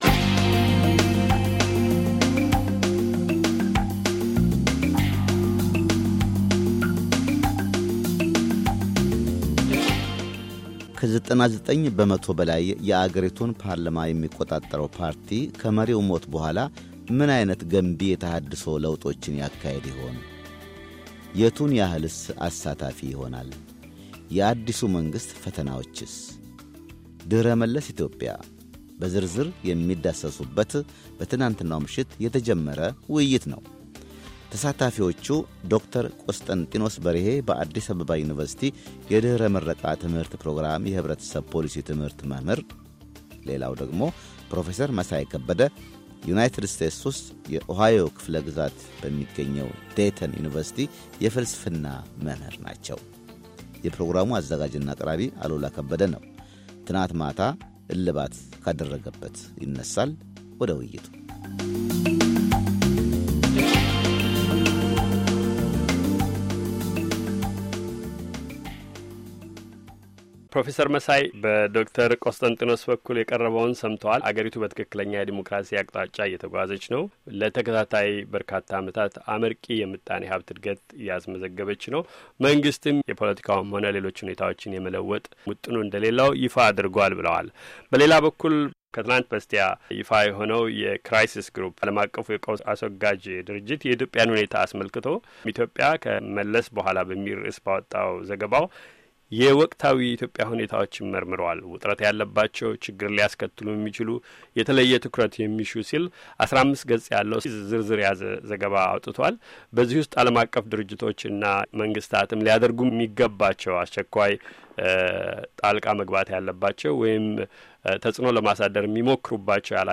ውይይት፥ ድህረ መለስ ኢትዮጵያ፤ አማራጮችና ፈተናዎች